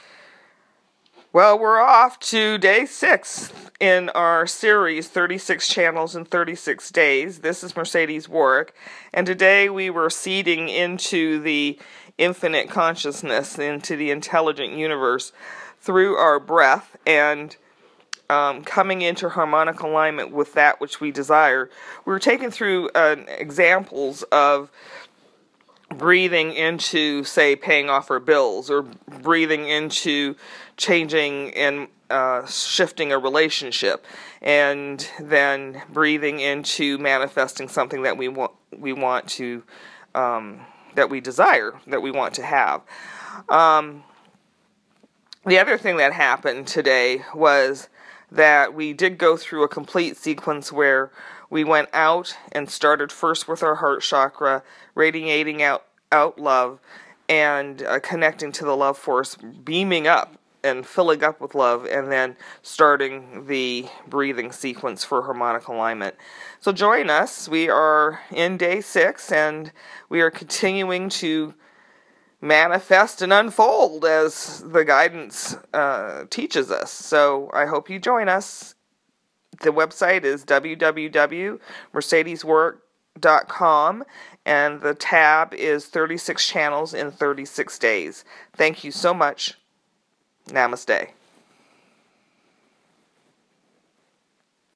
This audio recording is primary a breath sequence.